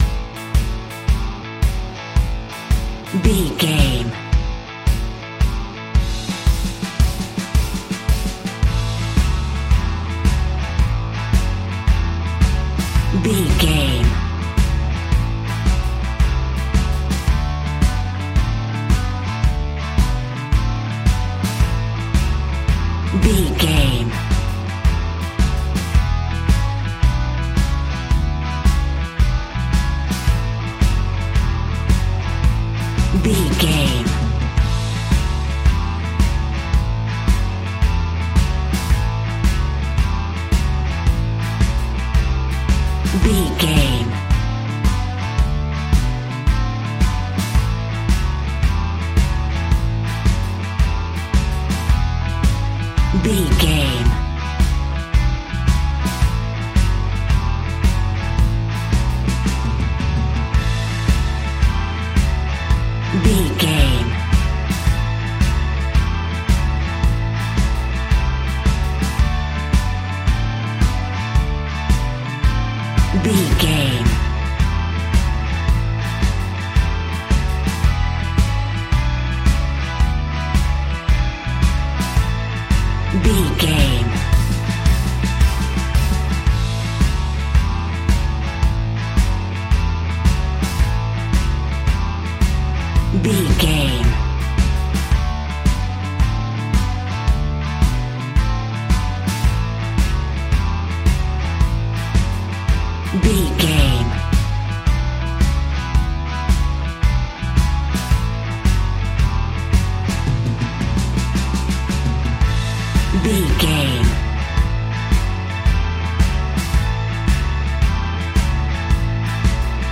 Ionian/Major
cheerful/happy
positive
light